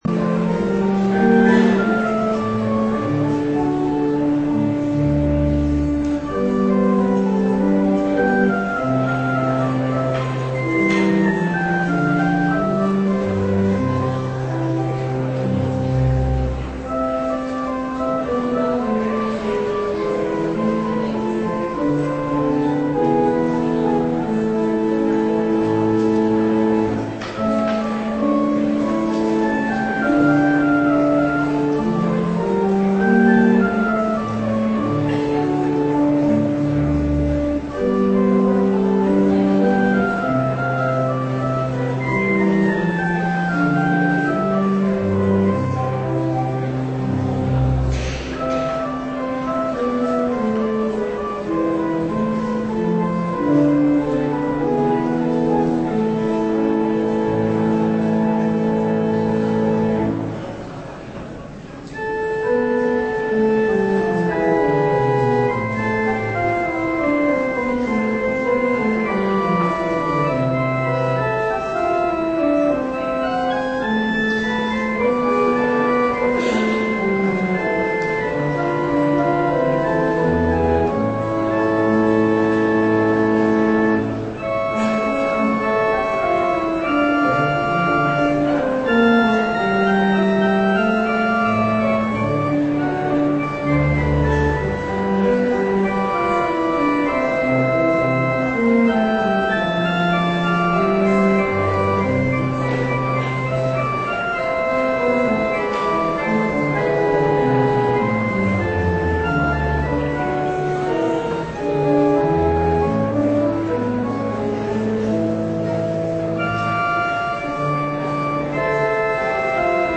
Preek over Efeze 3:14-21 tijdens de gezamenlijke fusieviering in de Sint-Jan op zondagmiddag 15 januari 2017 - Pauluskerk Gouda